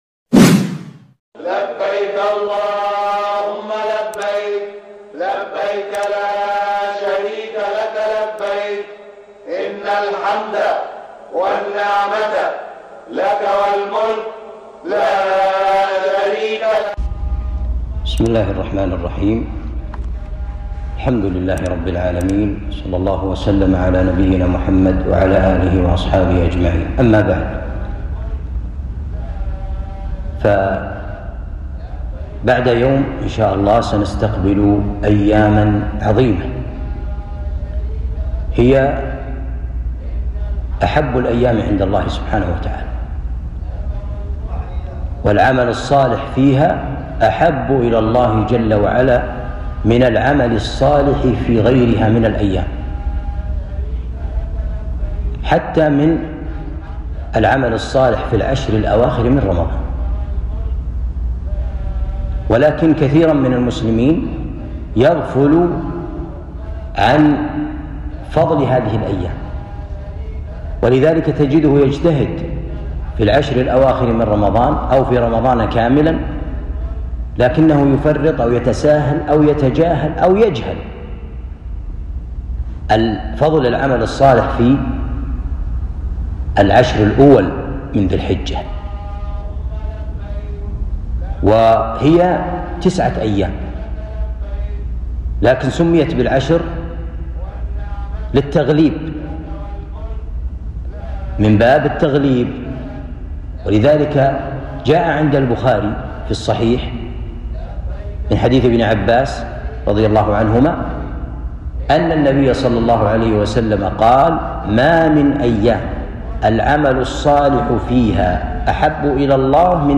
كلمة قيمة عن الإجتهاد بالعمل الصالح في عشر ذي الحجة